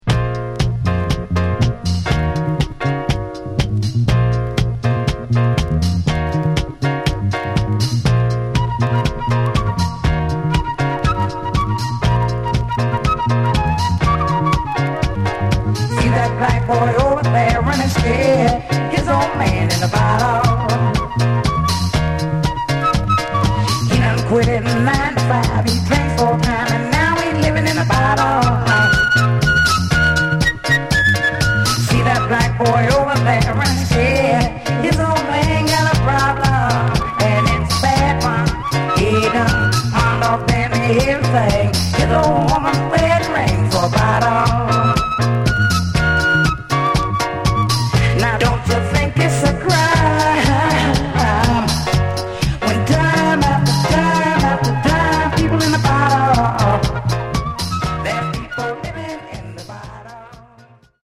Genre: Other Northern Soul
Dynamite Funky/Northern Soul!